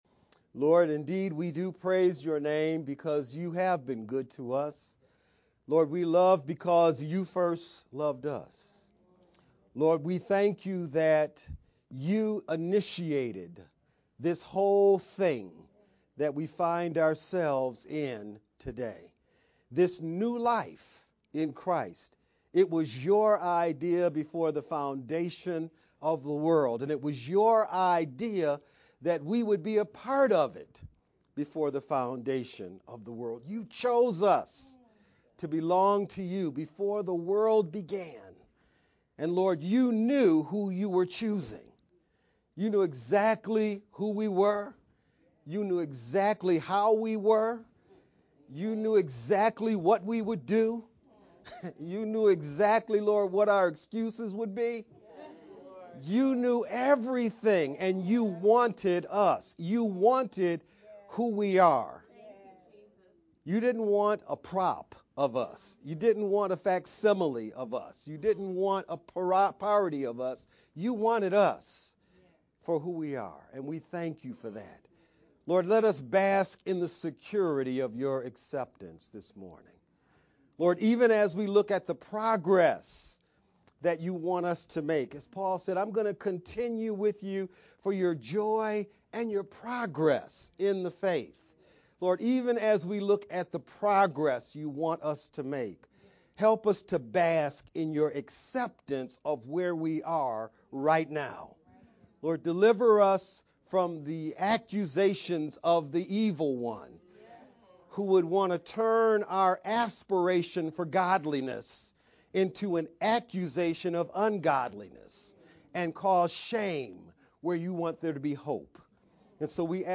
VBCC-Sept-15th-Sermon-only_Converted-CD.mp3